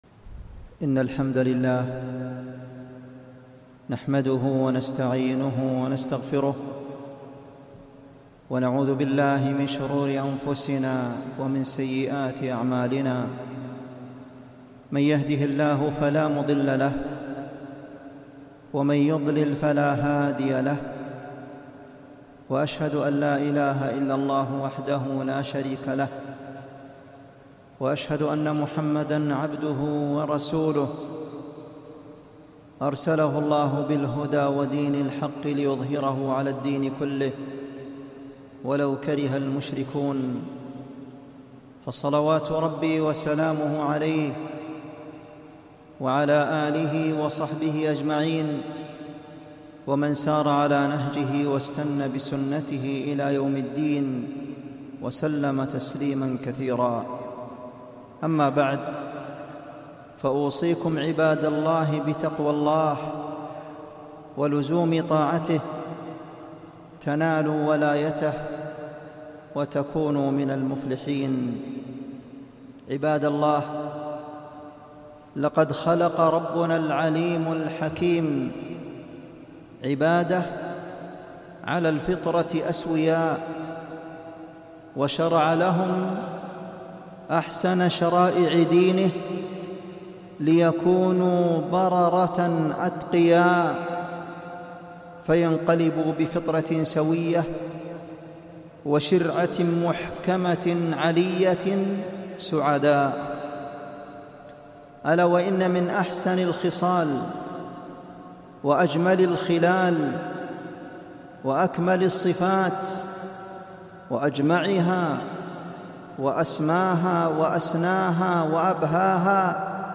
خطبة - الحث على الصدق والتحذير من ضده